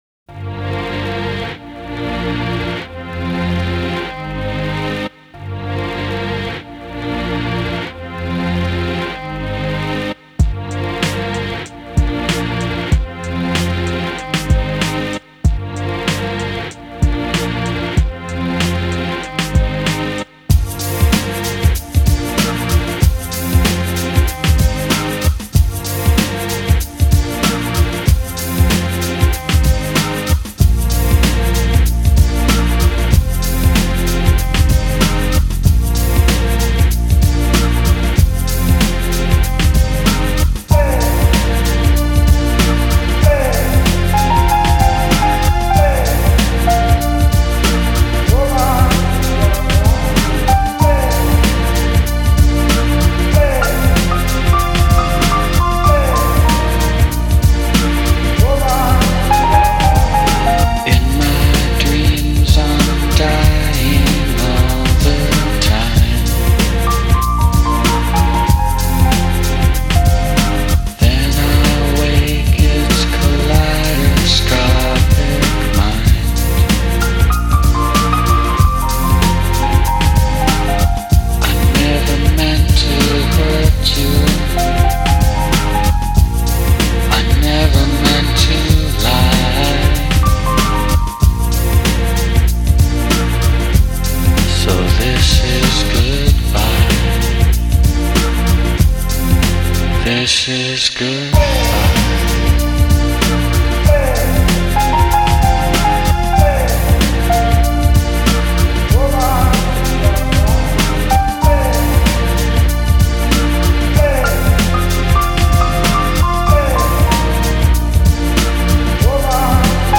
Файл в обменнике2 Myзыкa->Psy-trance, Full-on
Жанр: Electronic; Битрэйт